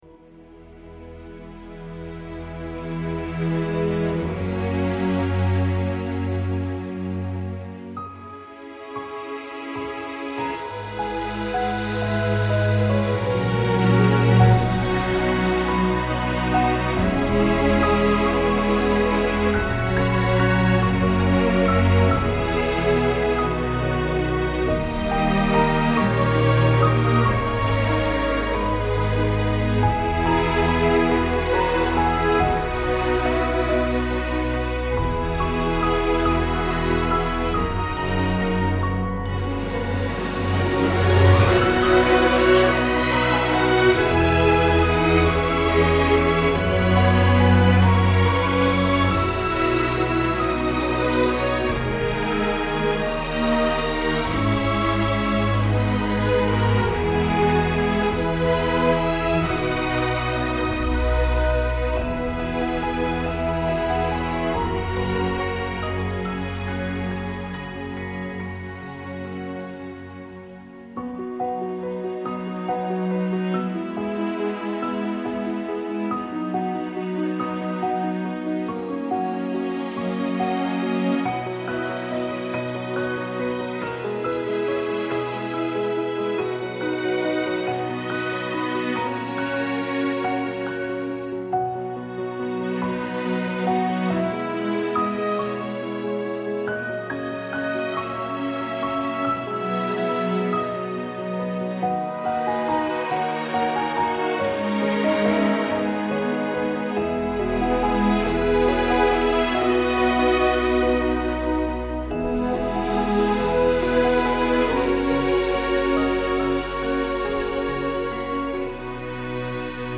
Partition triste, donc. Triste, triste, et encore triste.